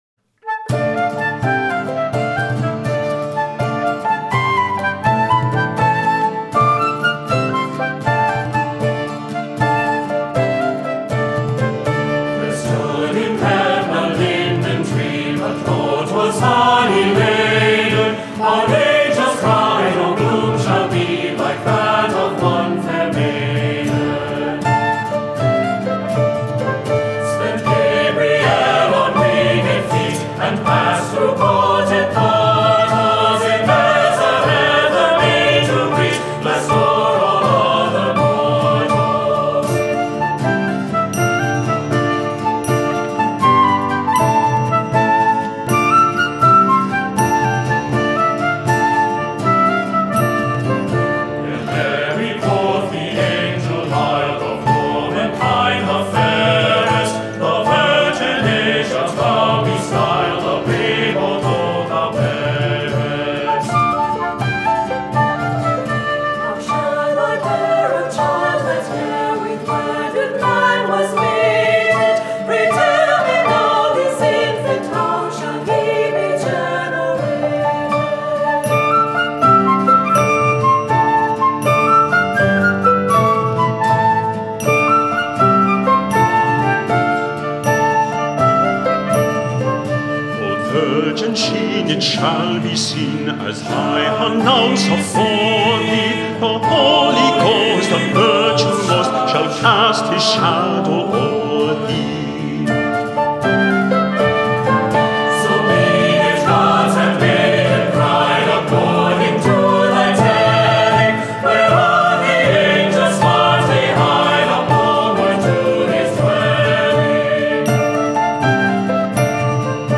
Voicing: "SATB, Tenor Soloist"